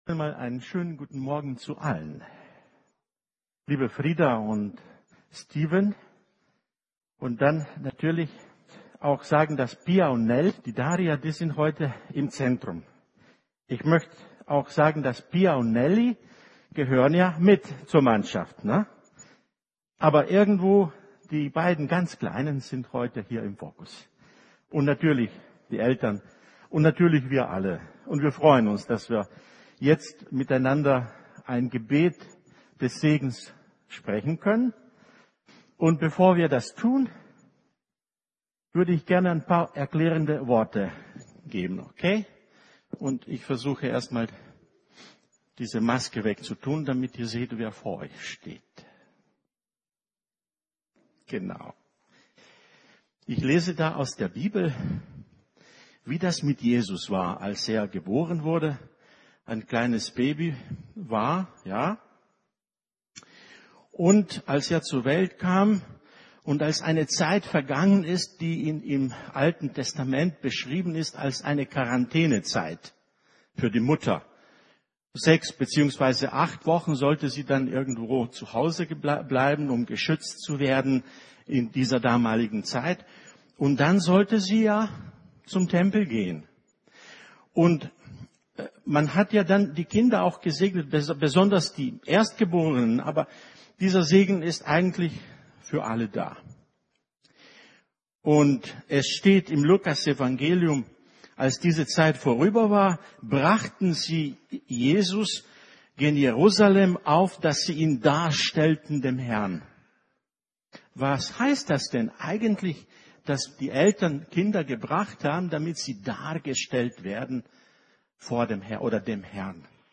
Kindersegnung 02.04.22